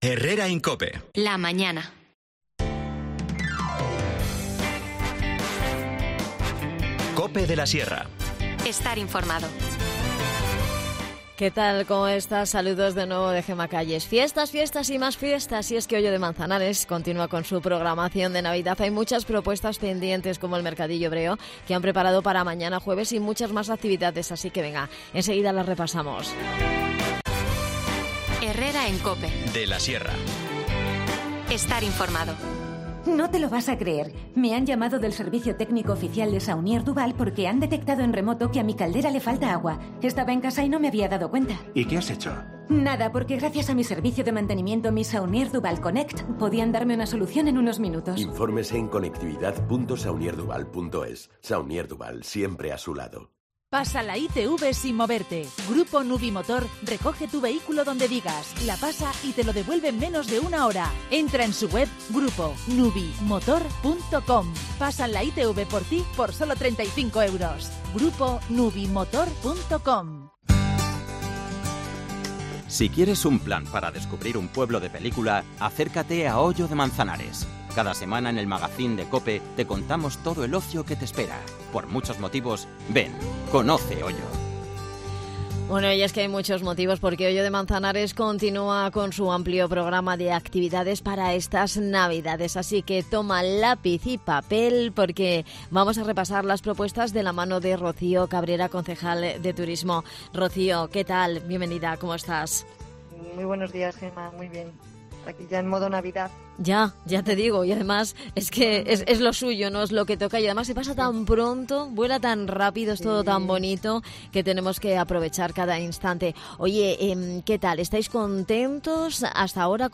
Nos detalla la programación Rocío Cabrera, concejal de Turismo.